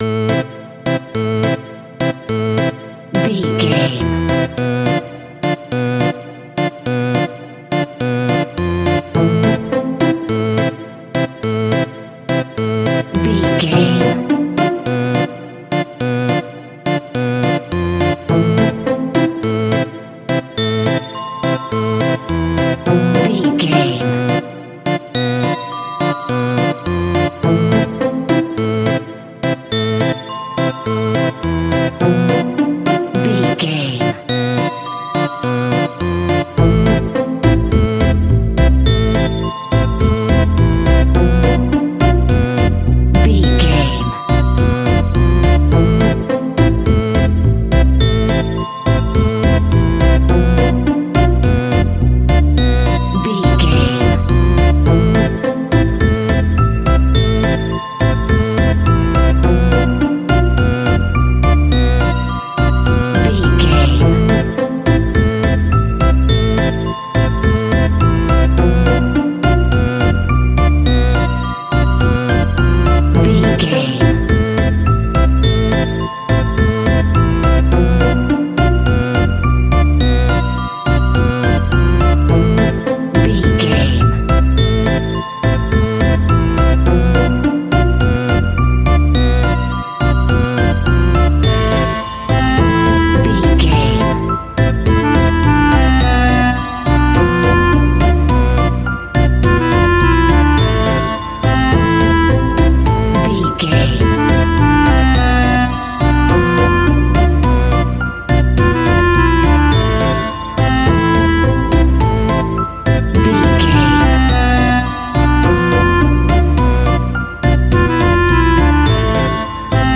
Aeolian/Minor
tension
ominous
dark
eerie
synthesizer
percussion
bass guitar
spooky
horror music
Horror Pads
Horror Synths